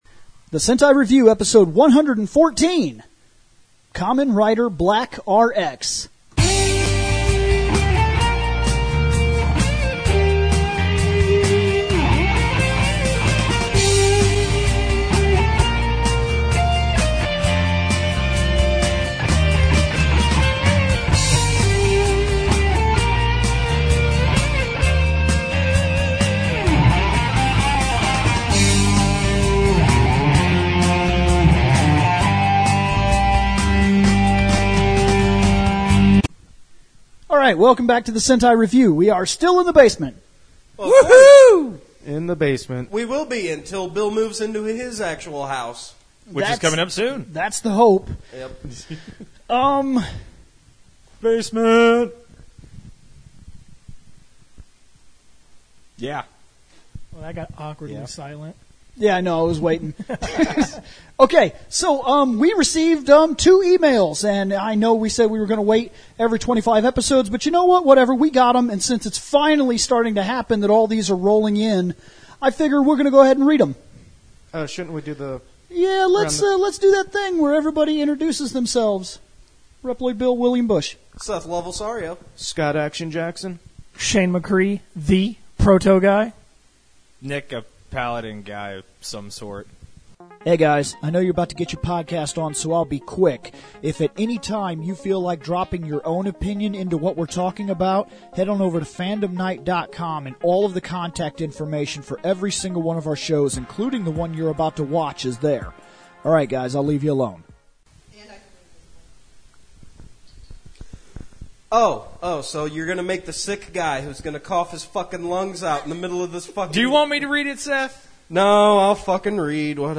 Hangin out in the game dungeon. Talkin about Riders.
We have a few laughs, and it starts as more of a What's Up episode than a Sentai episode.